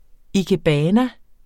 Udtale [ ikeˈbæːna ]